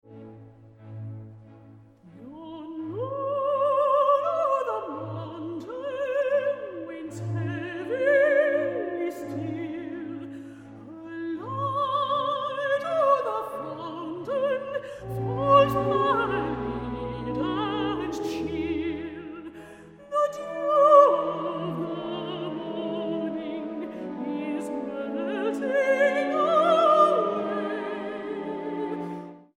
Arias from British Operas